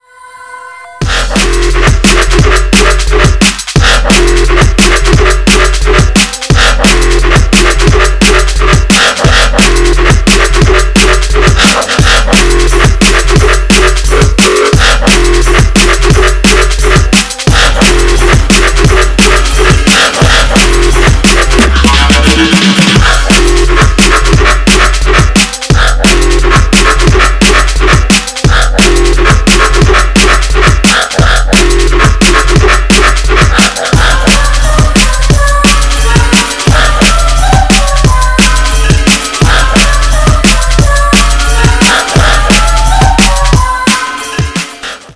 amb_winter_2.wav